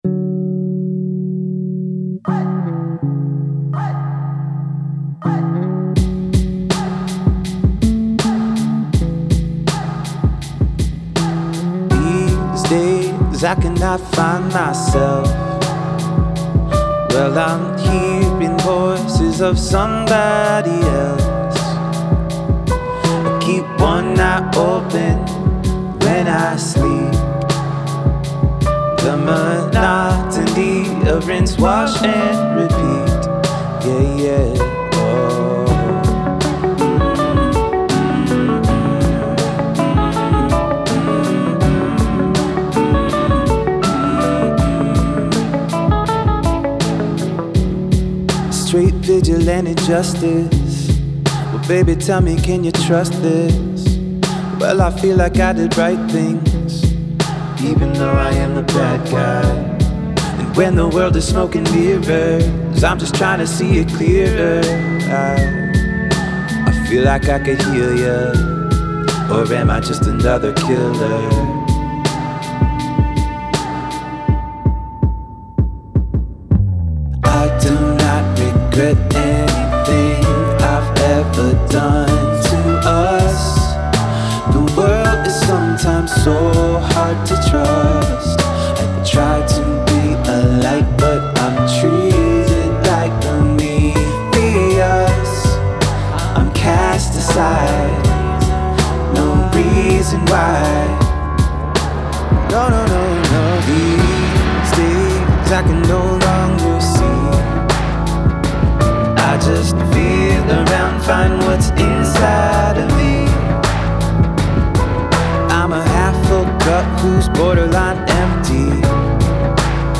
a song holding ethereal beauty